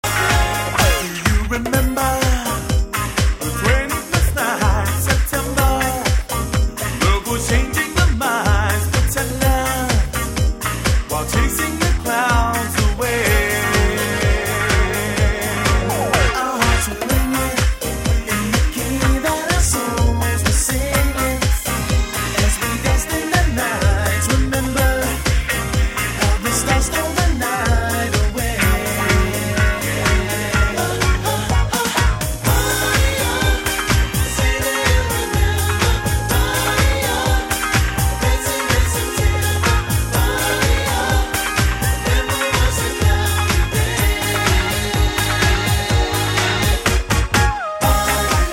drums/programming
bass
guitar/synth